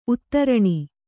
♪ uttaraṇi